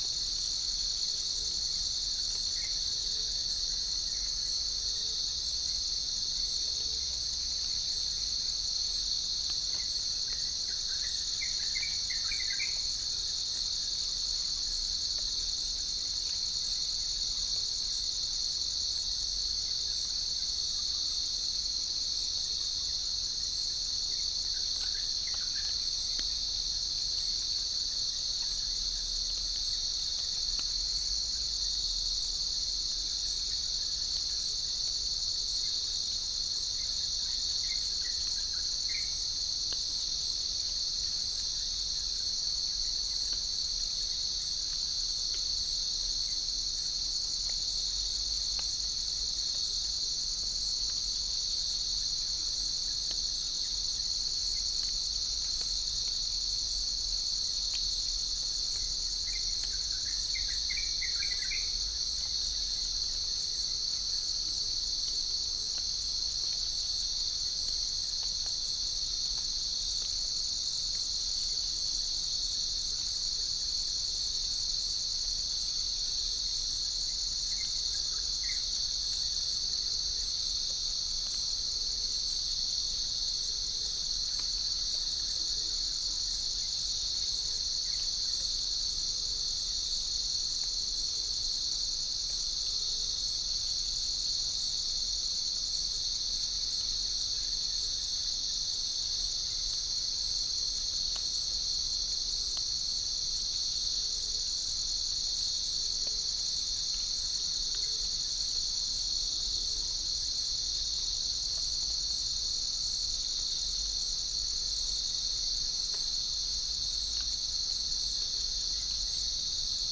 Chalcophaps indica
Centropus bengalensis
Pycnonotus goiavier
Halcyon smyrnensis
Orthotomus sericeus